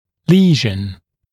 [‘liːʒn][‘ли:жн]повреждение, поражение, патологическое изменение